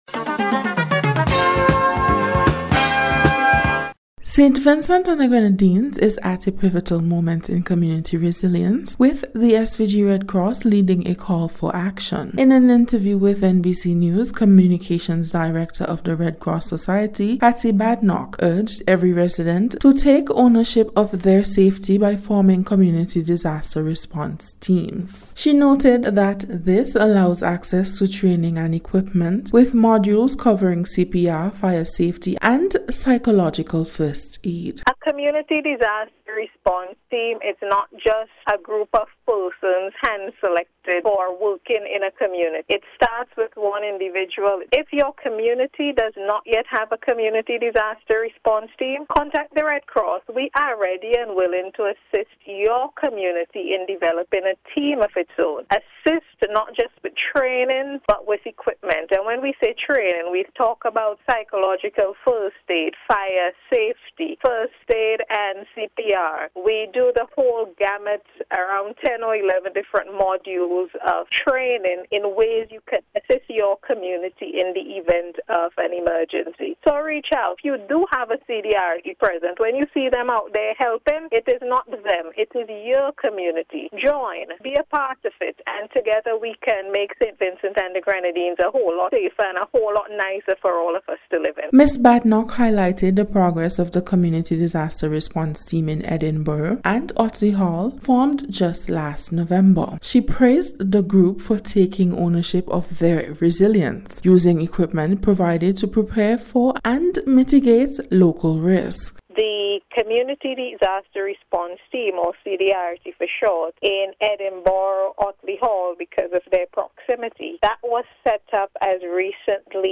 NBC’s Special Report-Wednesday 18th March,2026